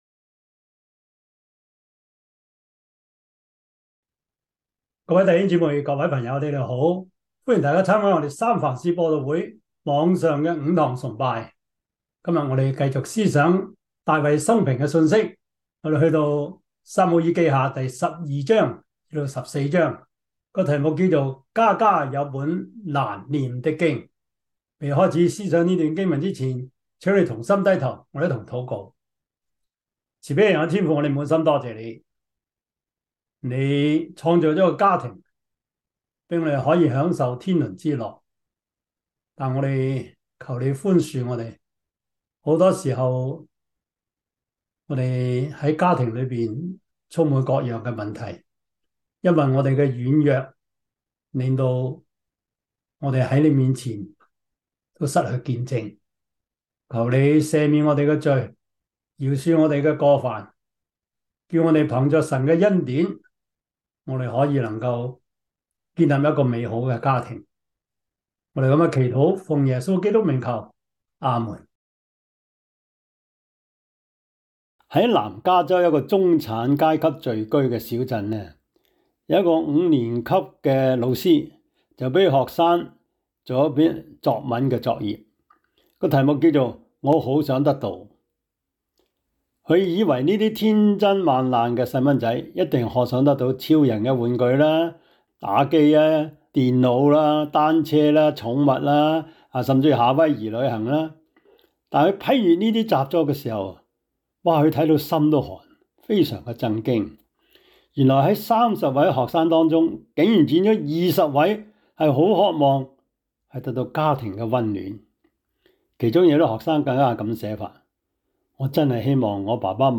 撒母耳記下 12:9-14 Service Type: 主日崇拜 撒母耳記下 12:9-14 Chinese Union Version
Topics: 主日證道 « 仰望等候耶和華 快樂人生小貼士- 第九課 »